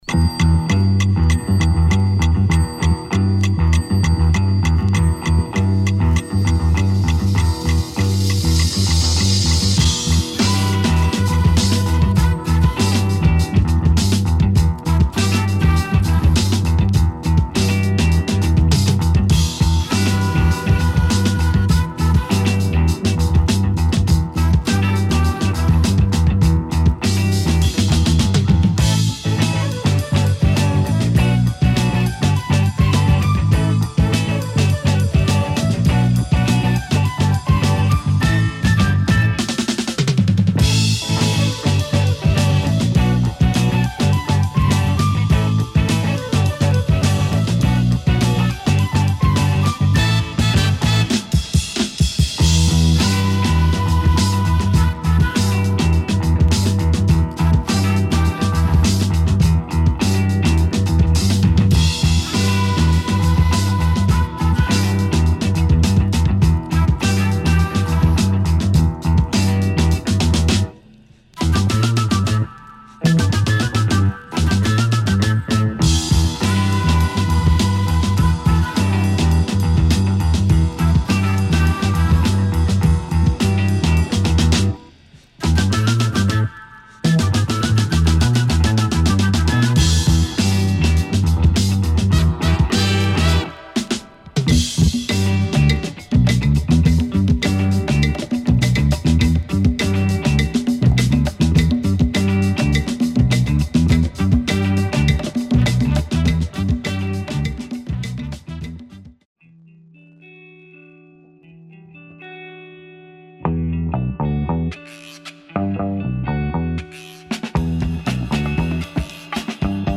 Killer Spanish funk instrumentals with a pinch of psych